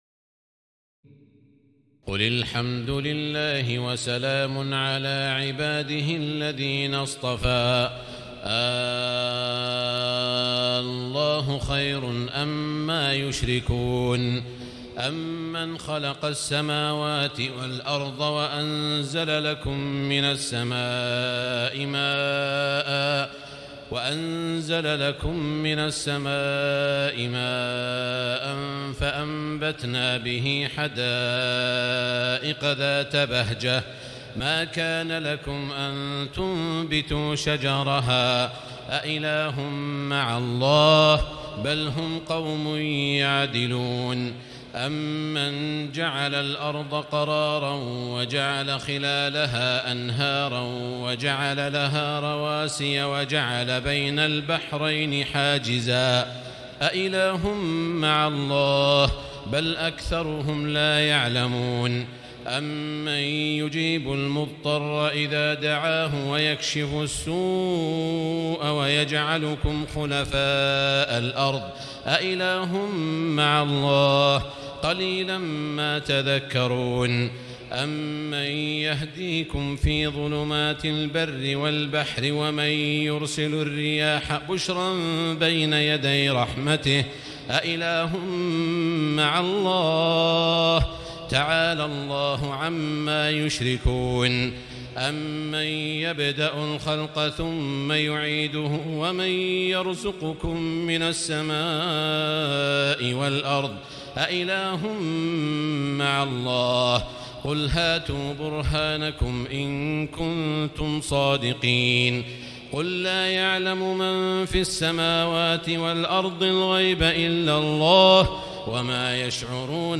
تراويح الليلة التاسعة عشر رمضان 1439هـ من سورتي النمل(59-93) و القصص(1-50) Taraweeh 19 st night Ramadan 1439H from Surah An-Naml and Al-Qasas > تراويح الحرم المكي عام 1439 🕋 > التراويح - تلاوات الحرمين